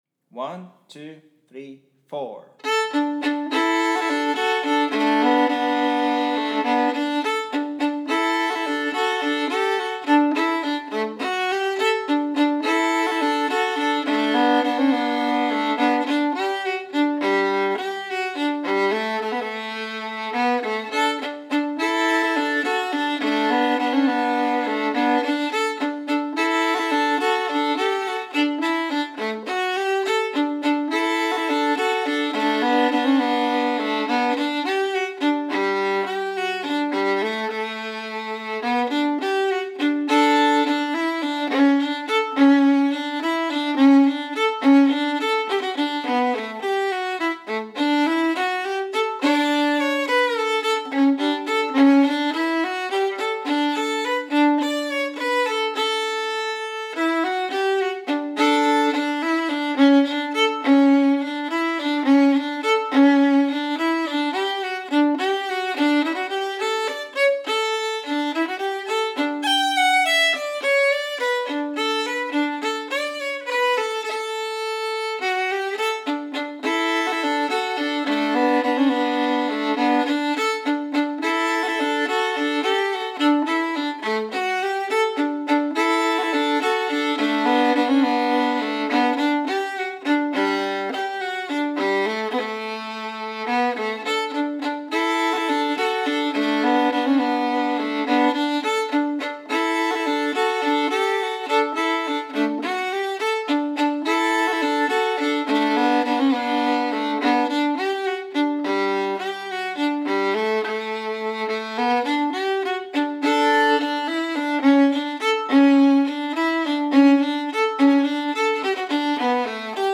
Stereo, 24bit/48khz